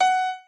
admin-fishpot/b_piano1_v100l4o6fp.ogg at main